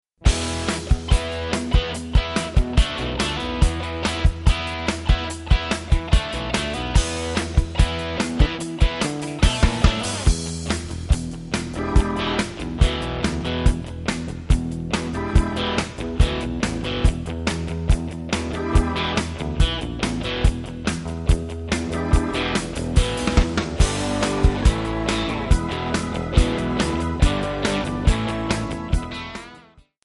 Backing track Karaoke
Rock, Oldies, 1960s